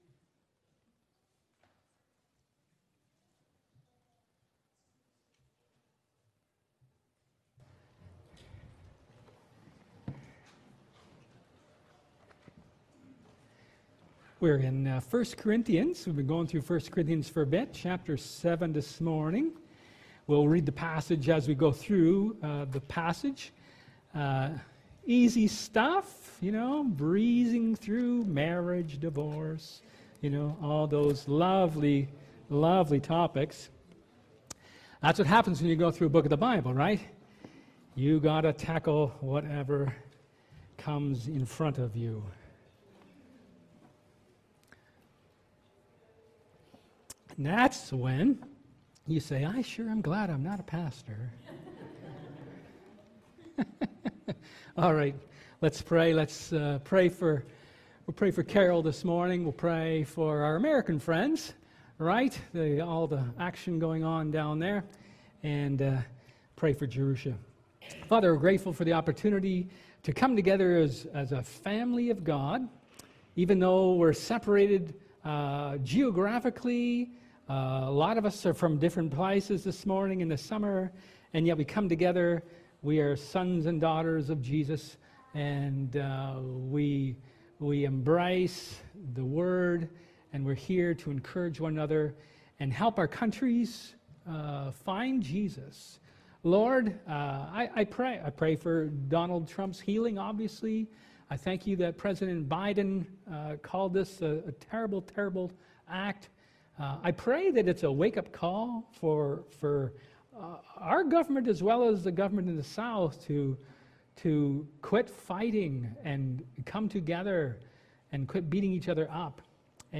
Luke 1:1-4 Service Type: Sermon